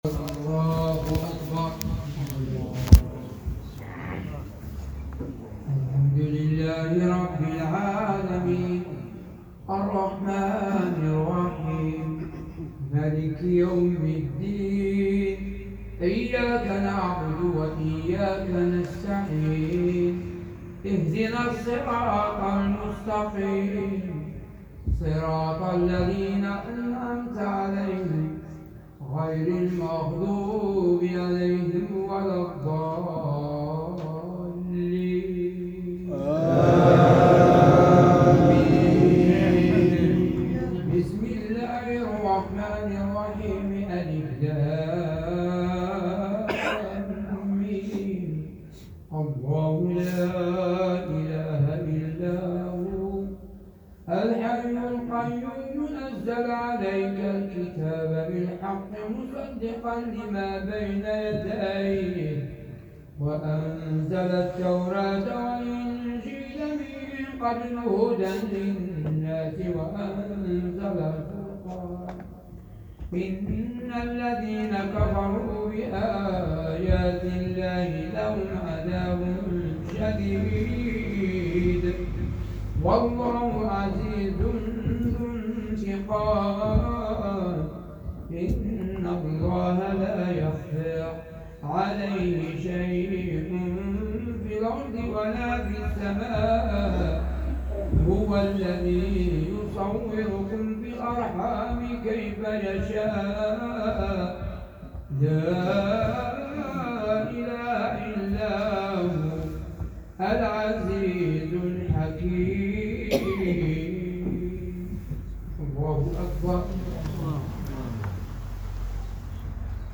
تلاوة جميلة وبديعة بالصيغة المغربية